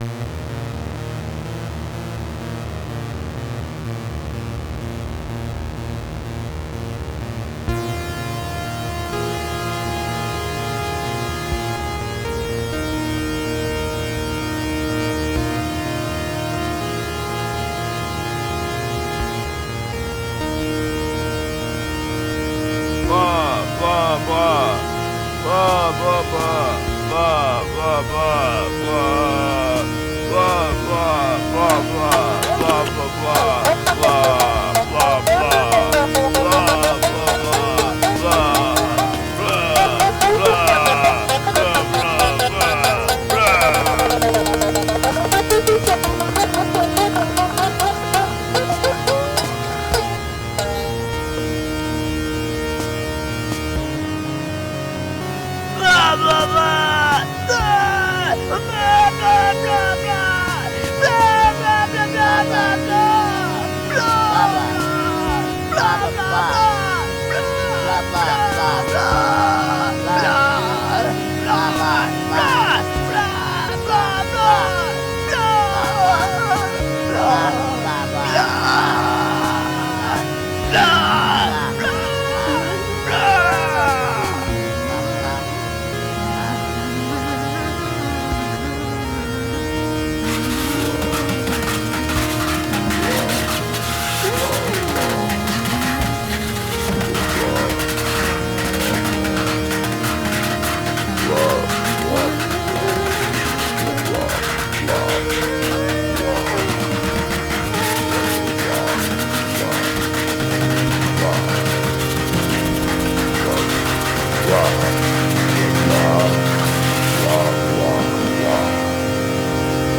Plugins: MinimogueVA